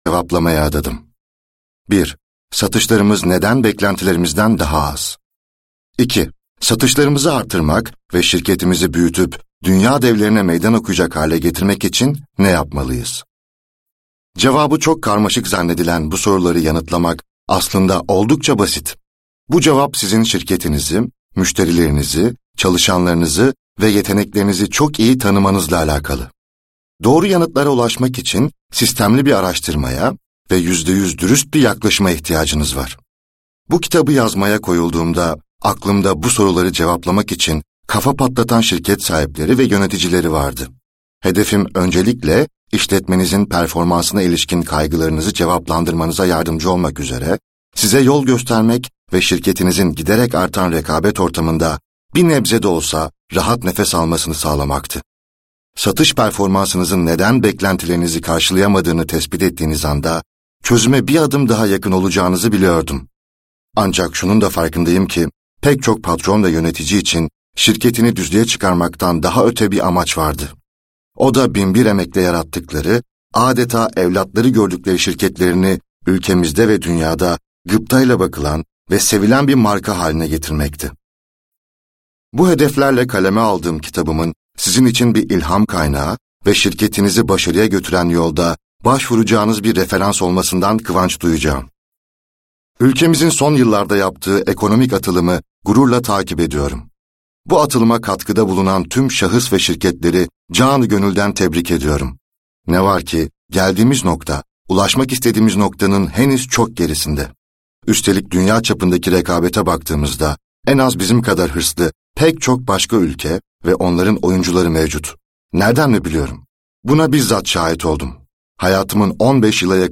Cenk Emre, 50’ye yakın örnek vaka analizi eşliğinde ve bir polisiye roman sürükleyiciliğinde aktardığı deneyimleri, pazarlama, satış, strateji ve inovasyon konularında alın teri döken profesyonelleri eşine az rastlanır bir yolculuğa çıkartıyor. Bu Kayıtta Cenk Emre’nin Yerli Malı Stratejiyle Dünyaya Kafa Tutmak kitabından ”Ürün ve Hizmetler” başlıklı bölümü dinleyebilirsiniz.